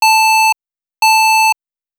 sfx_computer_bleep_1.wav